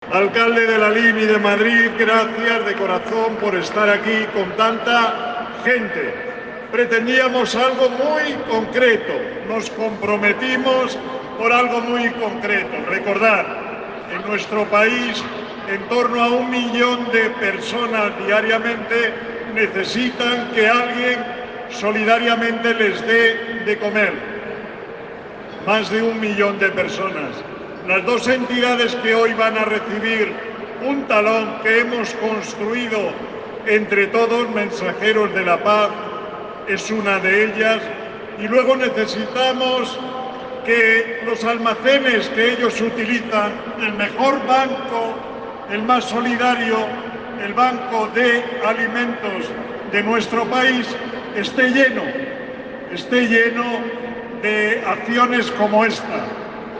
El evento, celebrado en el pabellón 6 del Recinto Ferial de Ifema Madrid -cedido de forma solidaria y gratuita-, concitó el apoyo y presencia de autoridades y personalidades polítias y sociales y empresariales. Así, a todos los presentes Miguel Carballeda, presidente del Grupo Social ONCE, agradeció el gesto solidario ya que ayudará muchas personas que lo necesitan.